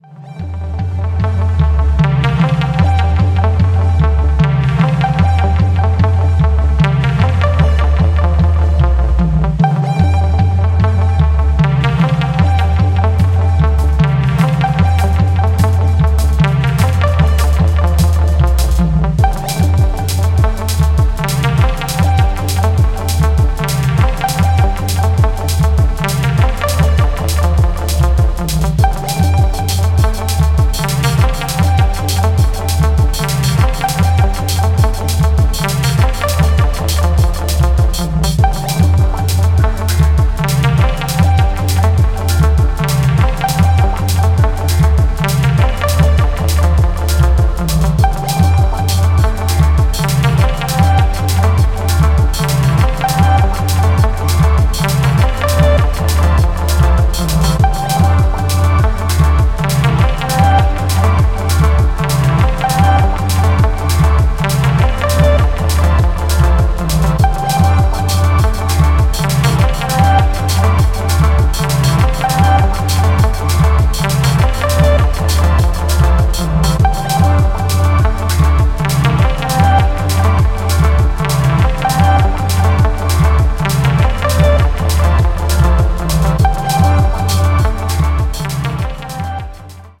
dusty and degraded sounding tracks